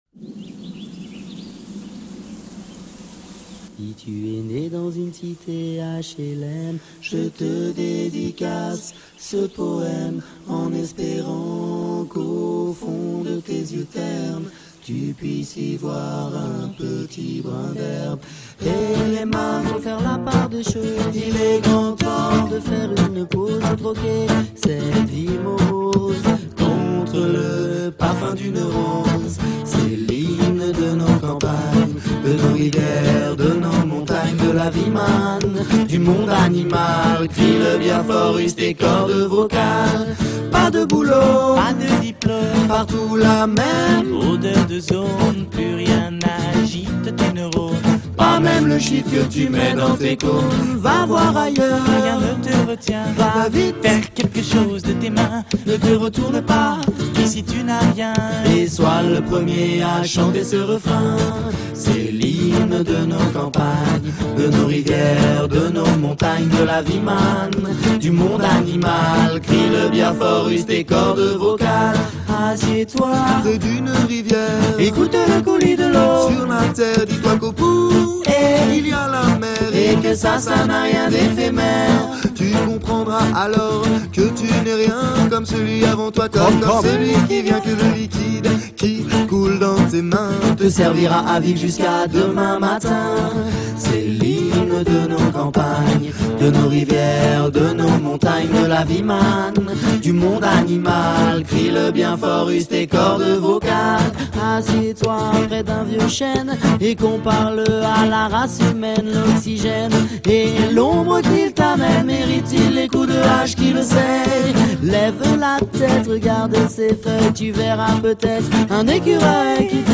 le mélange vocal des trois chanteurs du groupe